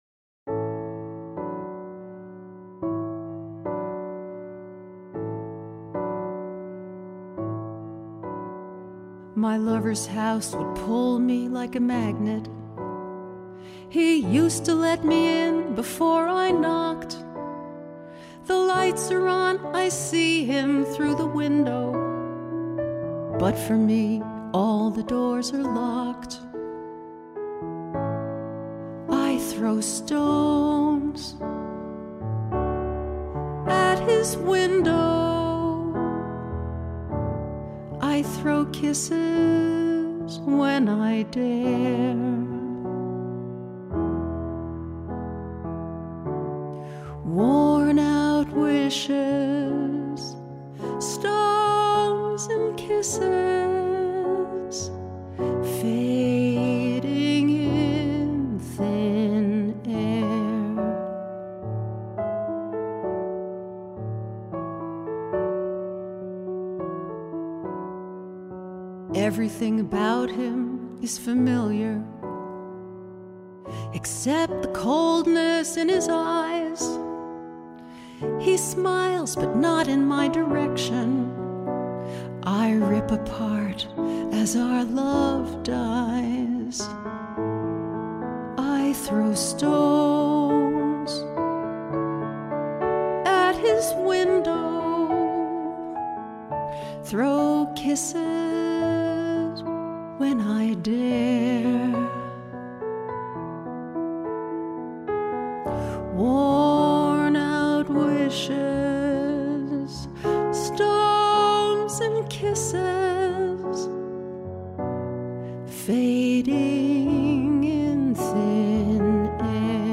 It turned into a love song about rejection and longing.